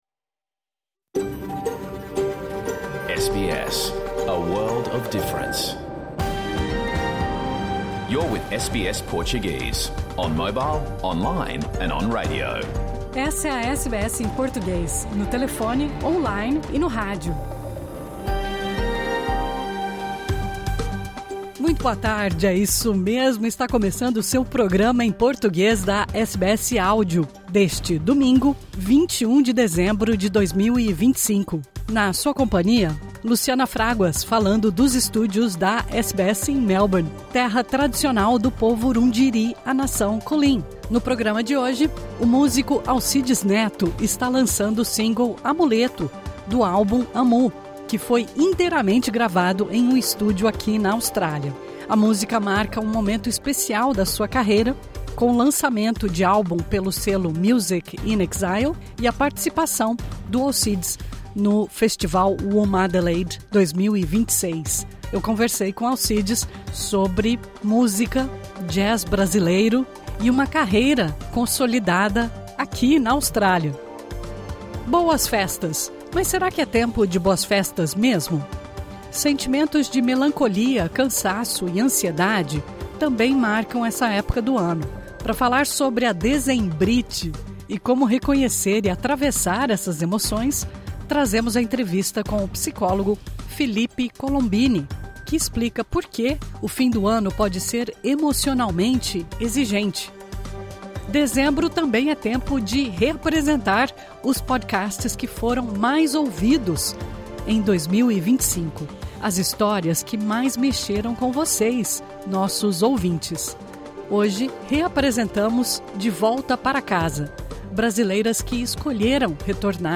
Programa ao vivo | Domingo 21 de dezembro